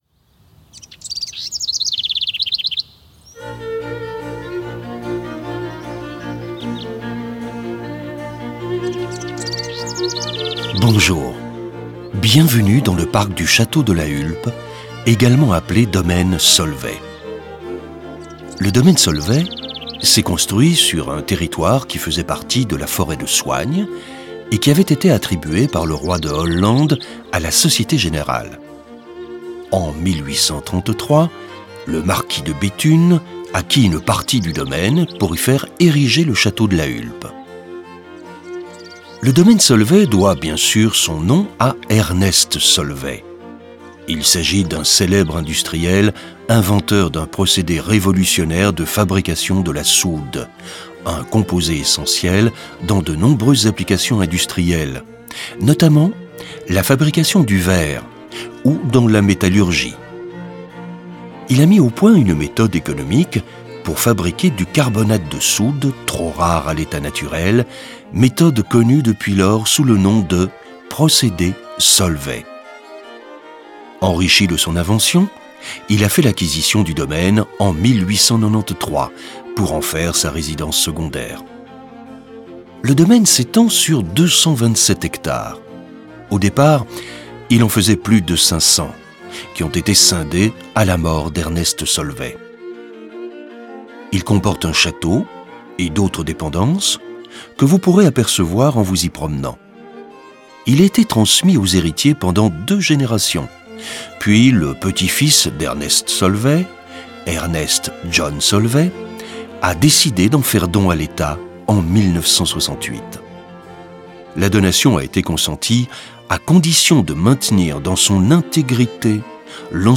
la_hulpe_audioguide_adulte_partie_00.mp3